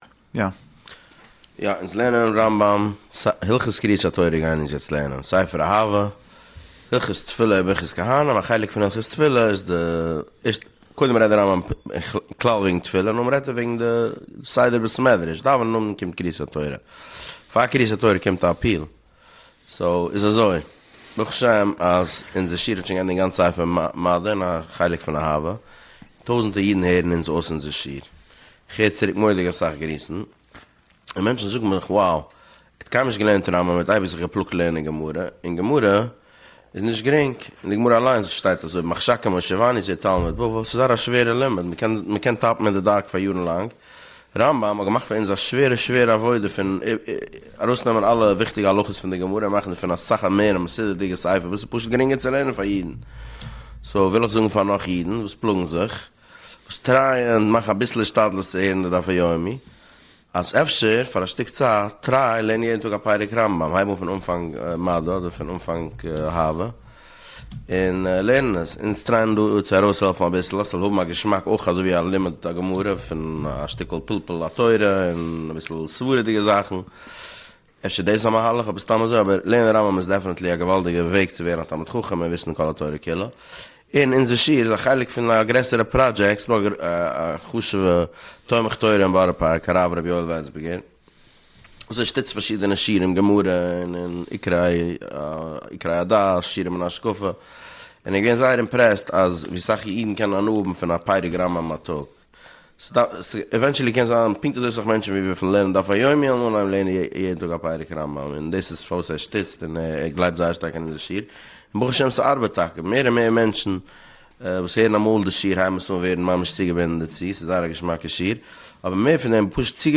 שיעורים על הרמב"ם פרק אחד ליום